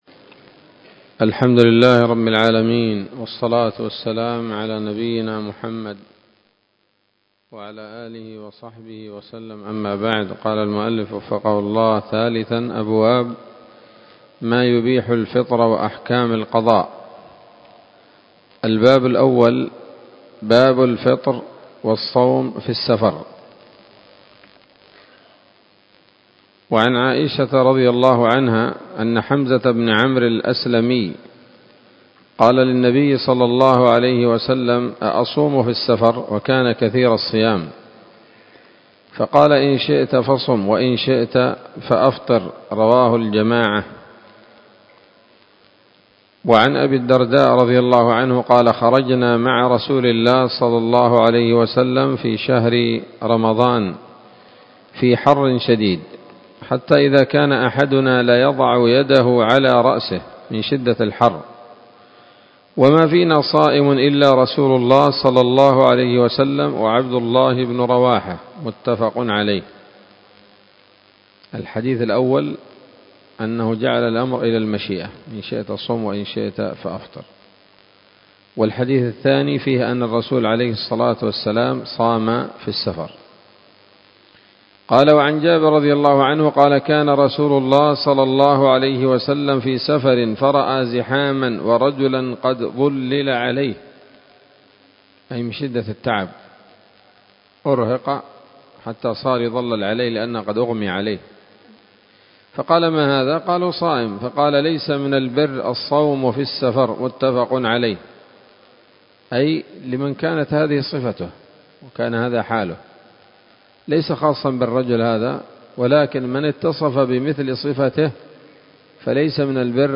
الدرس الرابع عشر من كتاب الصيام من نثر الأزهار في ترتيب وتهذيب واختصار نيل الأوطار